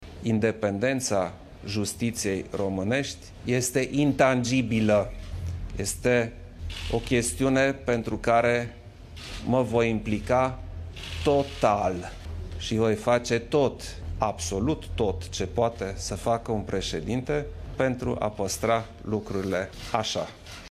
E o chestiune în care mă voi implica total şi voi face tot ce poate face un preşedinte”, a afirmat Klaus Iohannis, în conferinţa de presă comună cu preşedintele Comisiei Europene, Jean Claude Juncker.